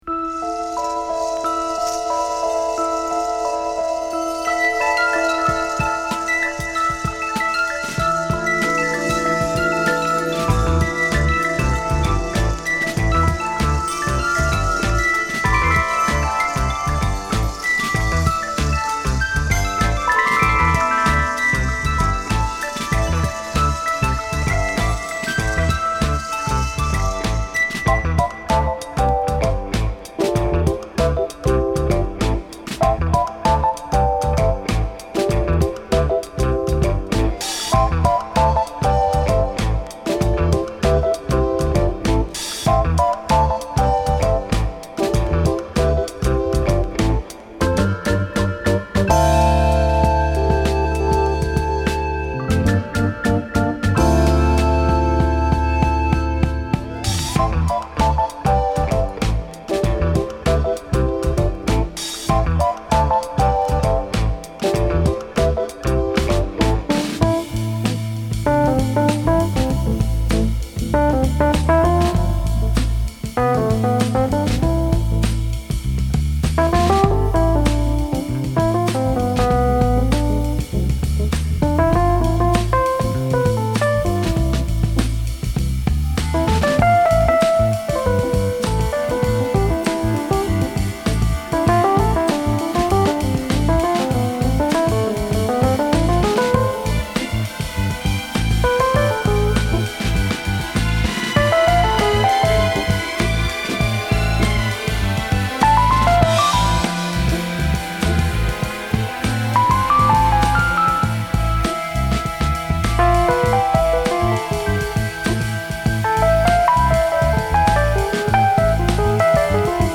浮遊感マンテンのエレピにスリリングなベースラインが印象的なキラーチューン！